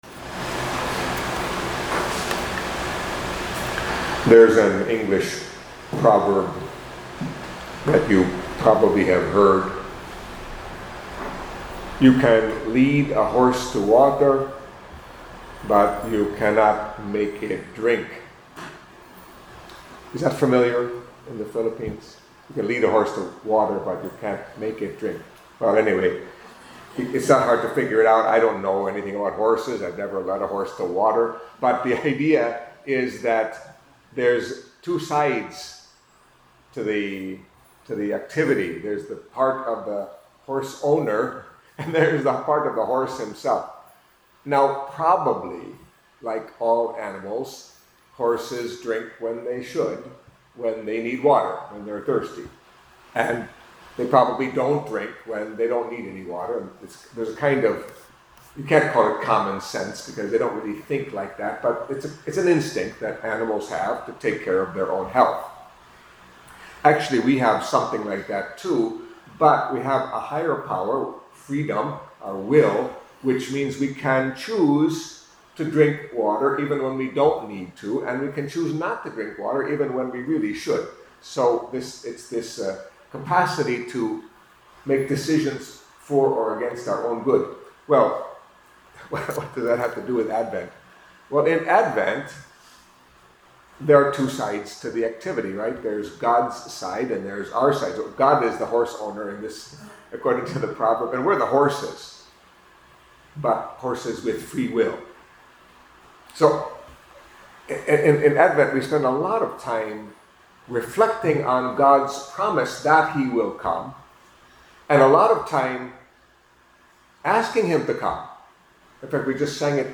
Catholic Mass homily for Wednesday of the Second Week of Advent